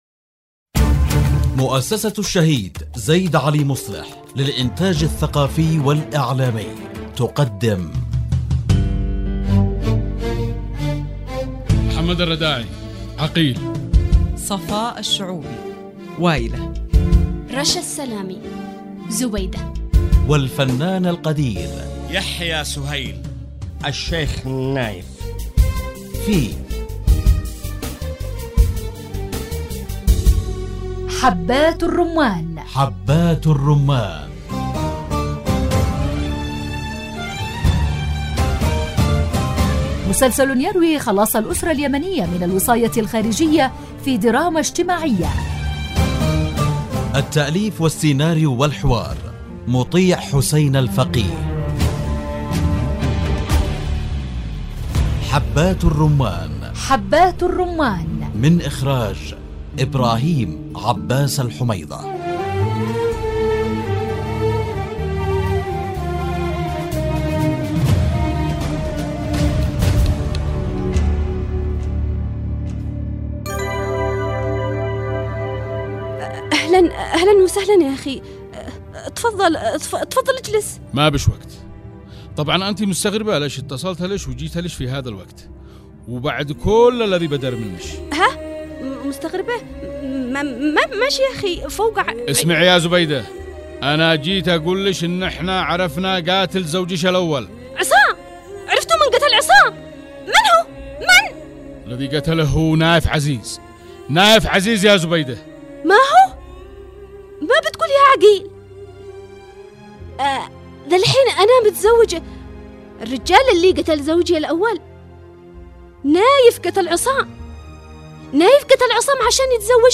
مسلسل يحكي خلاص الأسرة اليمنية من الوصاية الخارجية في دراما اجتماعية مع ألمع نجوم الشاشة اليمنية